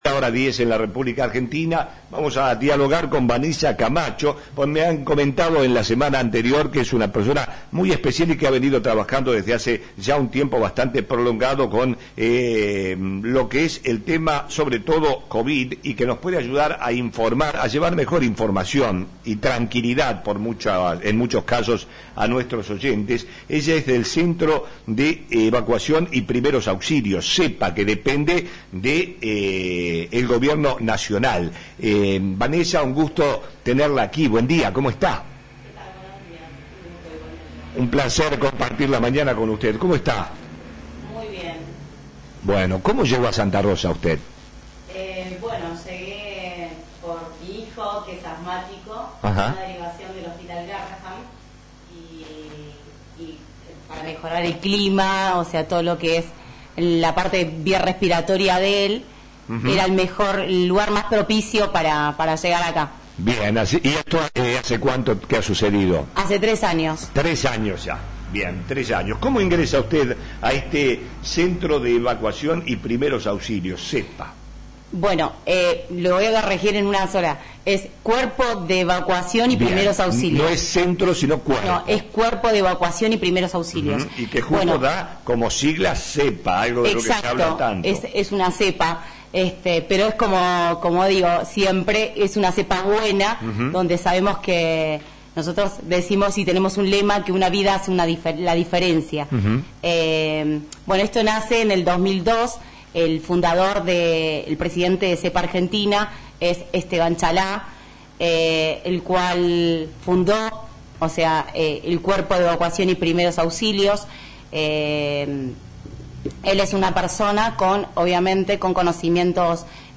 visitó nuestros estudios